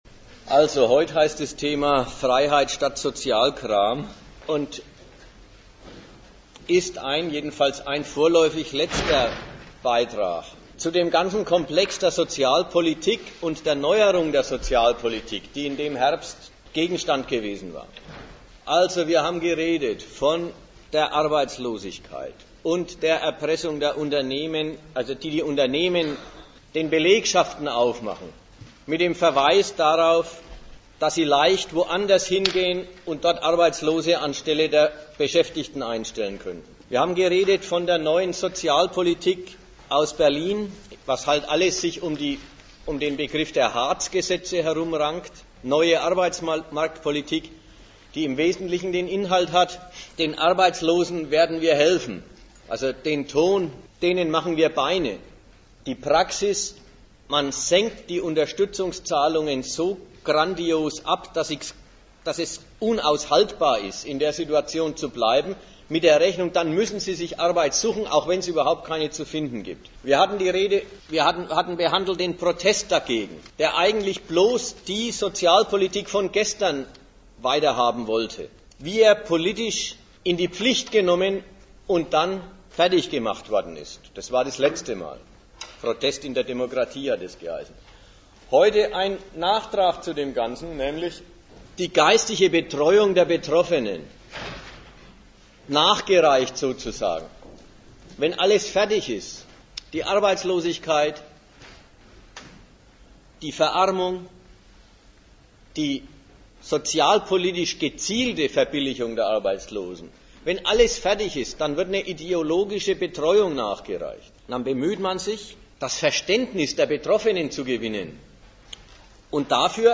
Nürnberg
Gastreferenten der Zeitschrift GegenStandpunkt
Mit Werten, ihrer Herkunft und ihrer Leistung befasst sich der Vortrag.